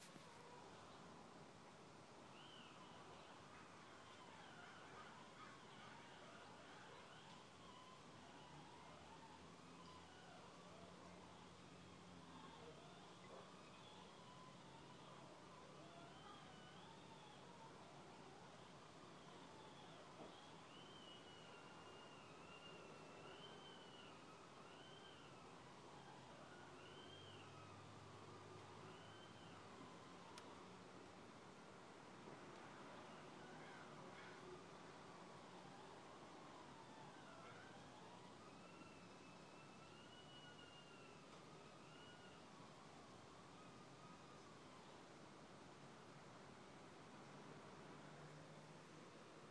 Theremin abuse?